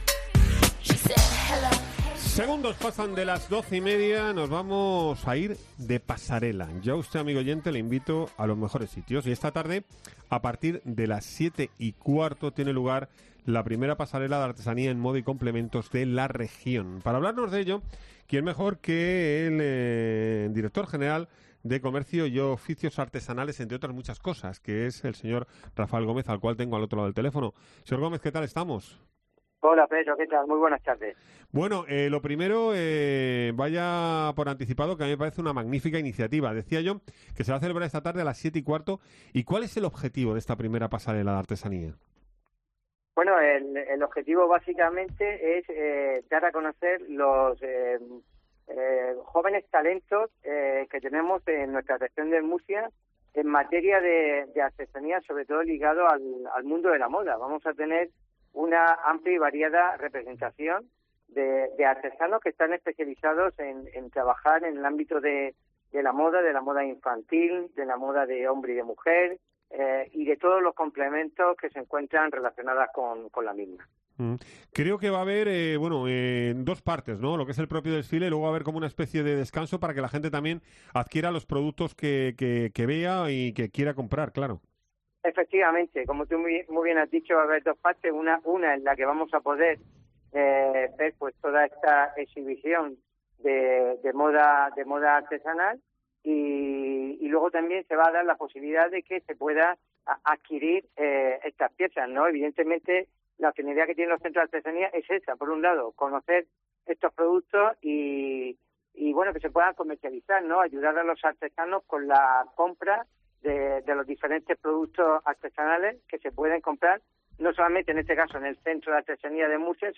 El directo general de Comercio, Rafael Gómez, habla de la primera pasarela de artesanía en Murcia
Así lo ha anunciado en COPE Murcia, el director general de Impulso al Comercio y Oficios Artesanales, Rafael Gómez.